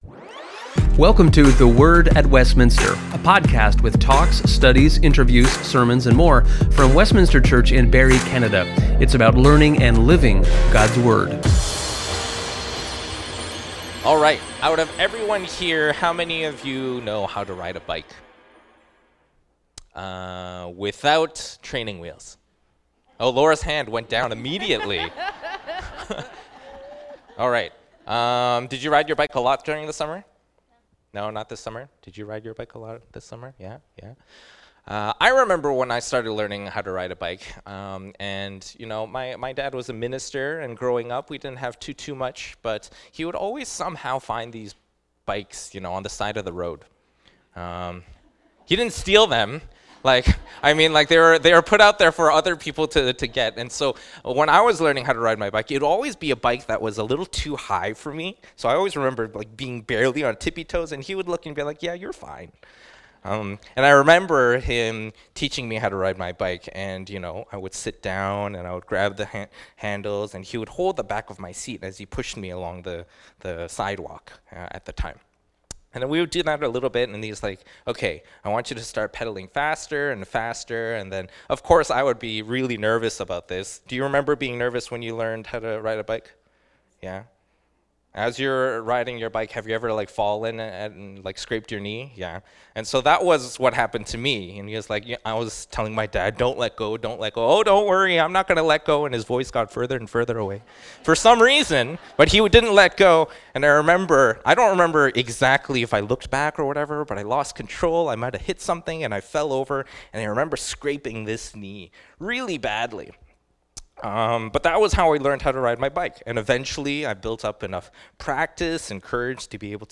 This episode is from the service on November 16, 2025.